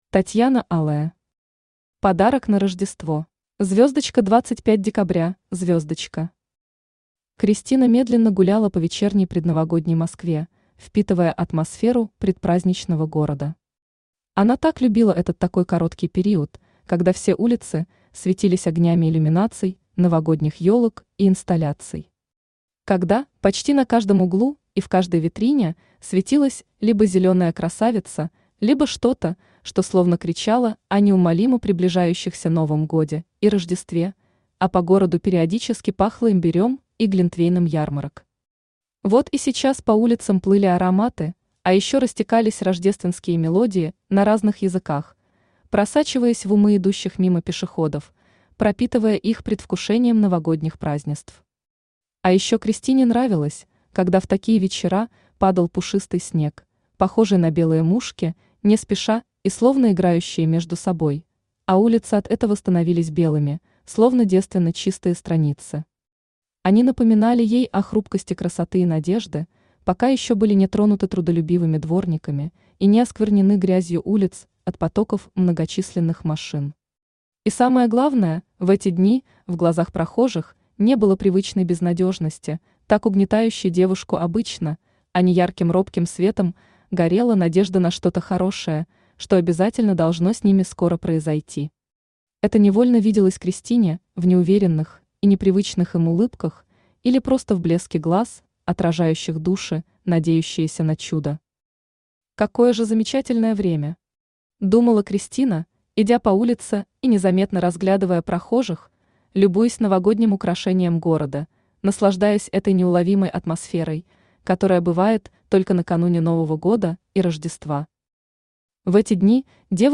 Аудиокнига Подарок на Рождество | Библиотека аудиокниг
Aудиокнига Подарок на Рождество Автор Татьяна Алая Читает аудиокнигу Авточтец ЛитРес.